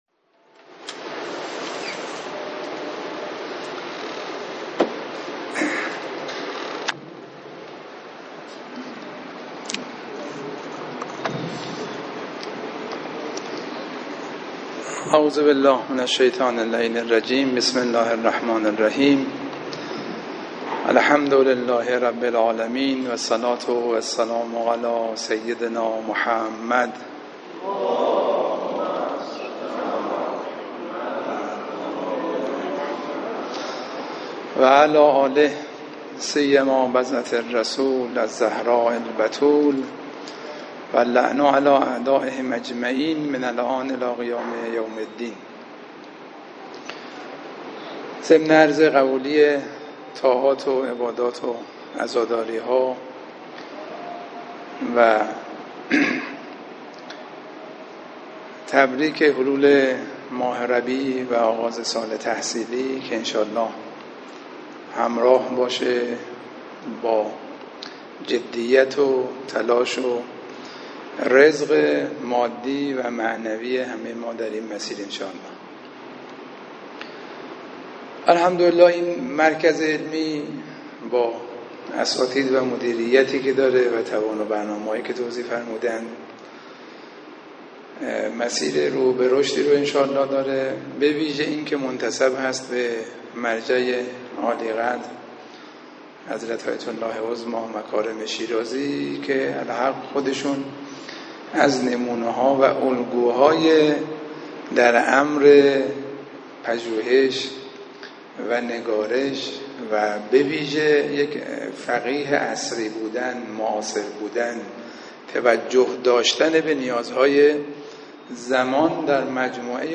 مراسمات
🔶آئین افتتاحیه سال تحصیلی 1402-1401 مدرسه عالی فقه و اصول امام حسين عليه السلام